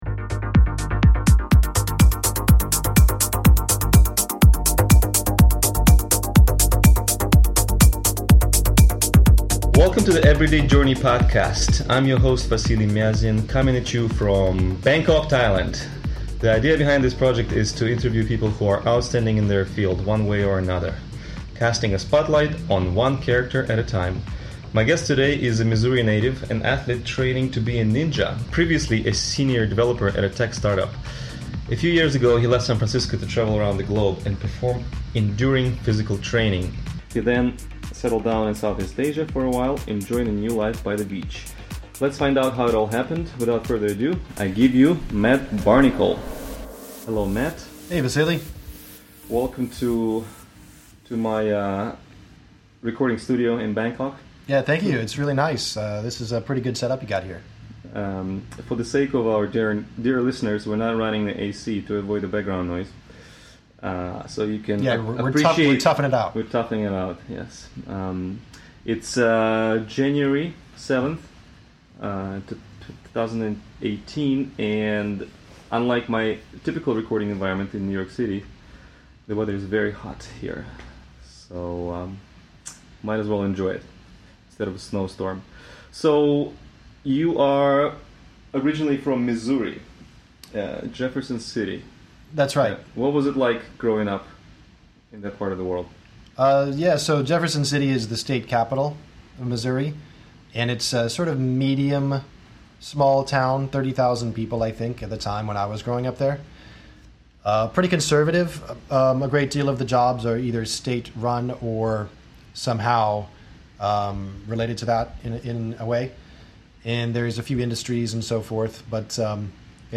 Having been friends for over 11 years, naturally, it was an easy conversation and a good chance to catch up while we both were in Bangkok. We covered an array of topics from quite deep to decidedly light-hearted.